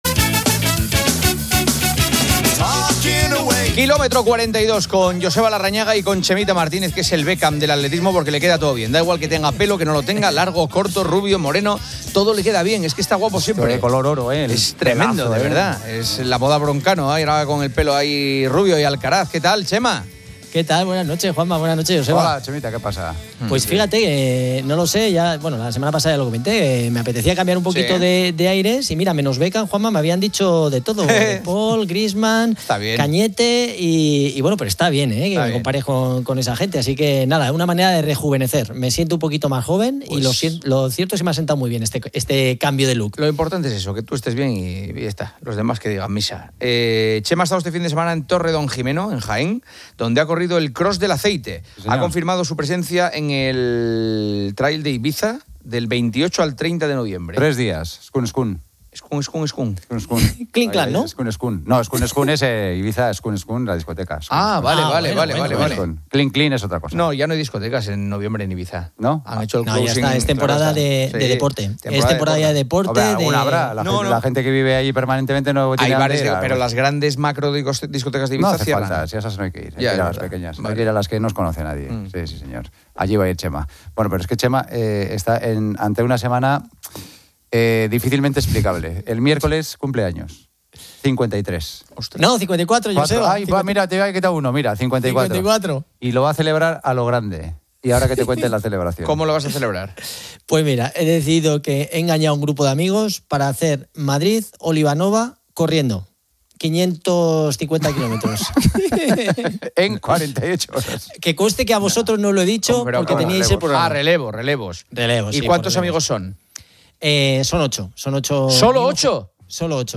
conversan sobre atletismo en COPE